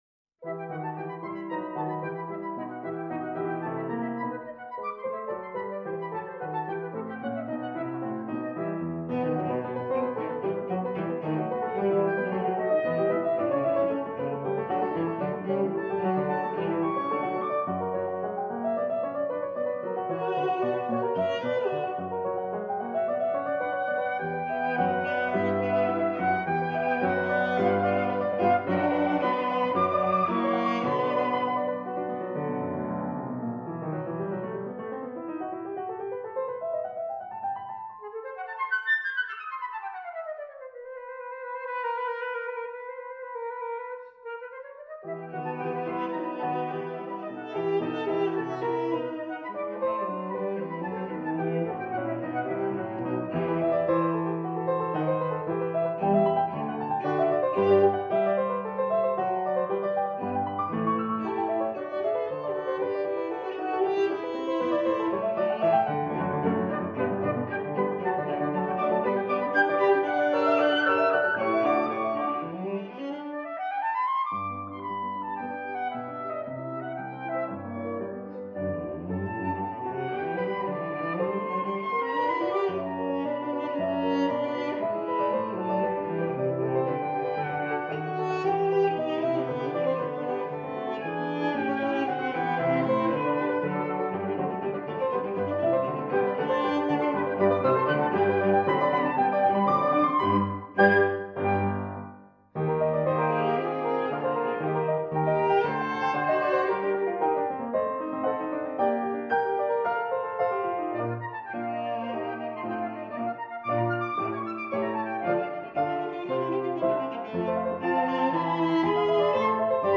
flute
cello
piano